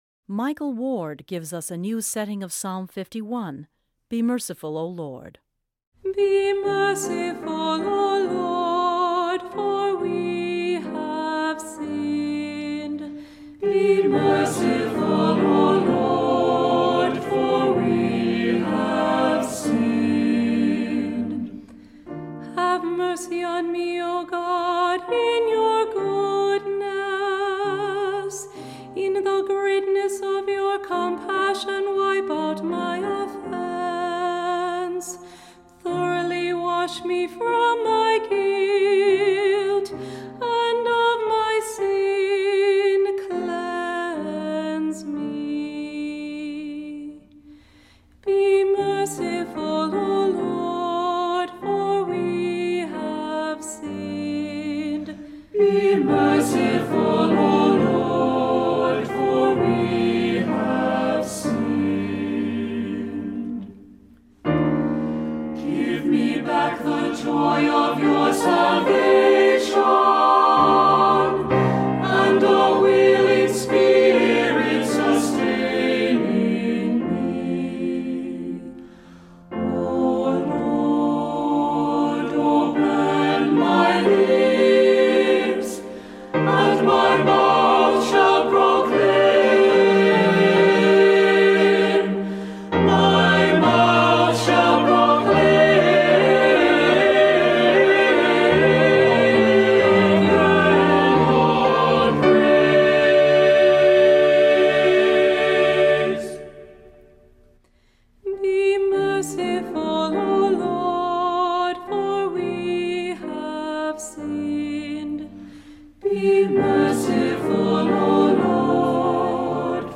Voicing: Assembly, cantor,SATB